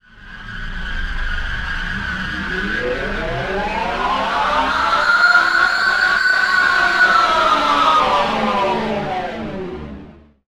RIZER.wav